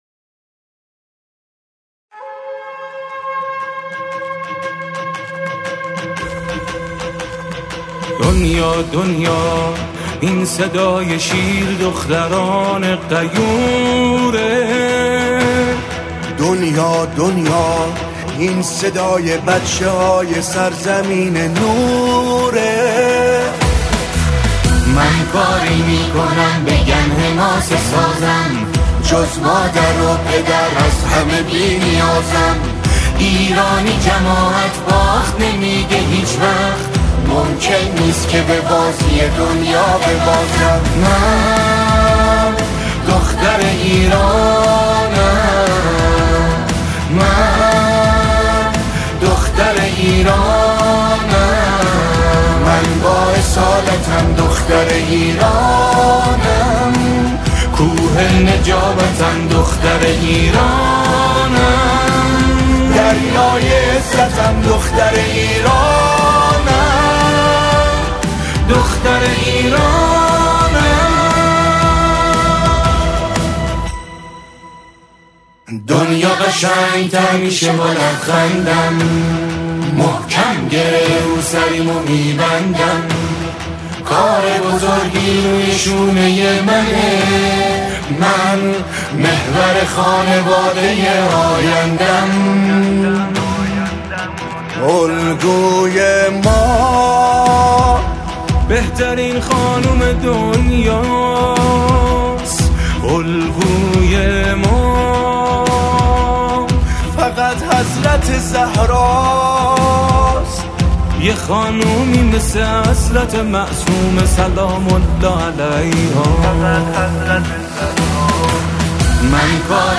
به صورت جمع خوانی به مرحله اجرا رسیده است.